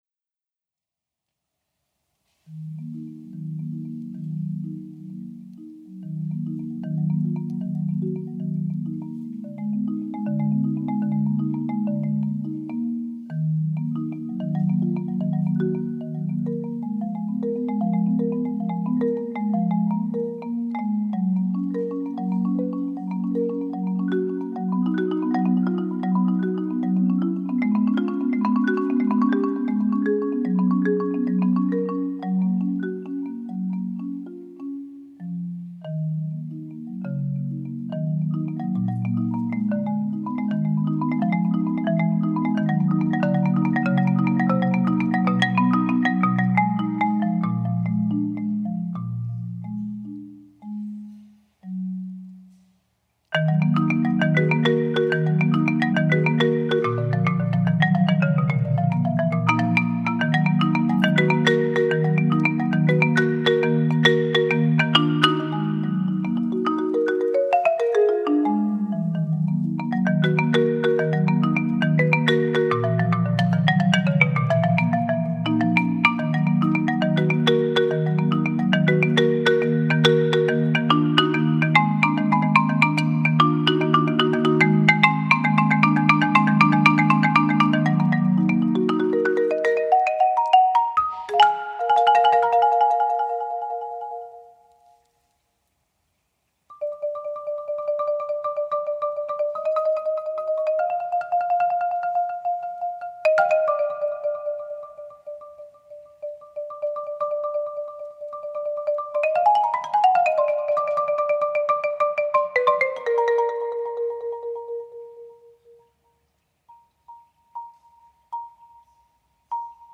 marimba, loop station, Latin percussion, fruit bowls
saxophone
guitar and percussion
'...recorded briljantly, sounds super relaxed......'.